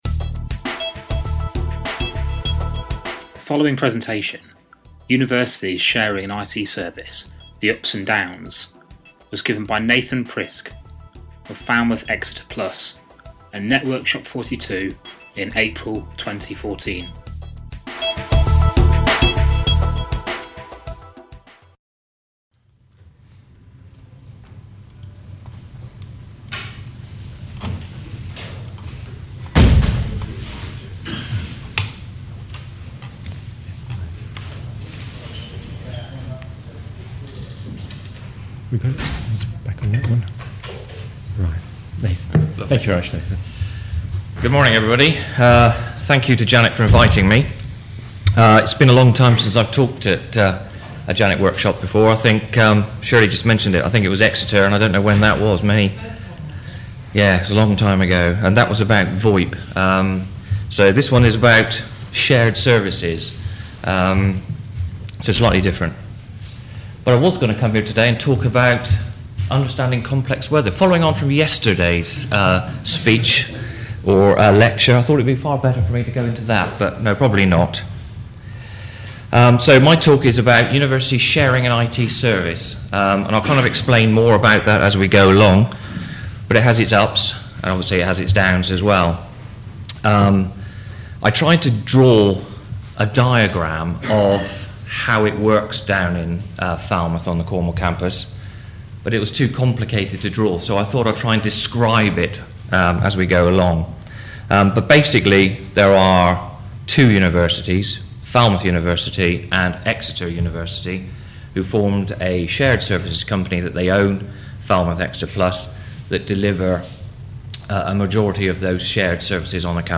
Networkshop 42